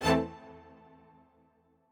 admin-leaf-alice-in-misanthrope/strings34_1_003.ogg at a8990f1ad740036f9d250f3aceaad8c816b20b54